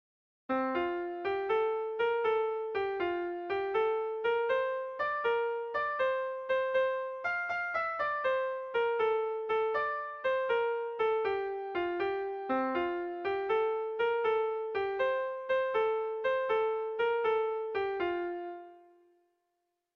Erlijiozkoa
ABDEA